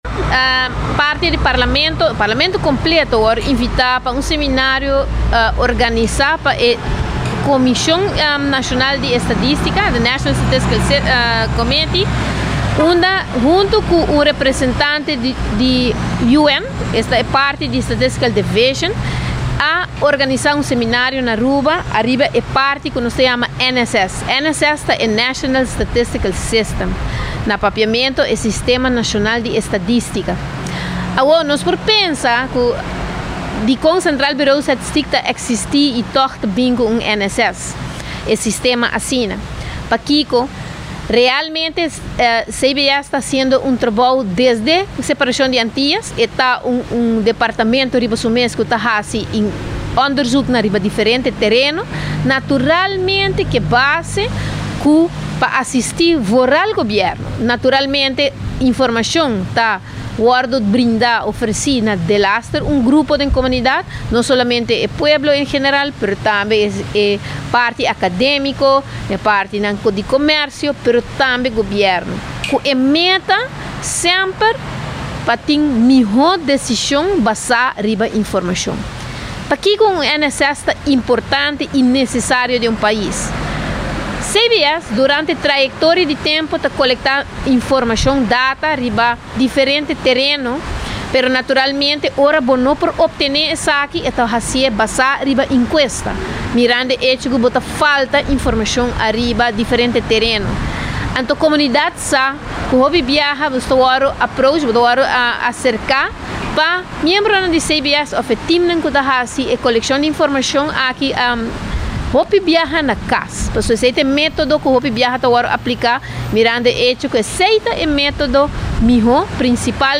Den conferencia di prensa di fraccion di MEP parlamentario Setty Yarzagaray a trece padilanti cu parlamento a keda invita pa participa na e seminario di sistema nacional di estadistica. Segun Setty Yarzagaray CBS ya caba ta haci diferente investigacion pa duna gobierno informacion cu e meta pa tin miho decision riba informacion.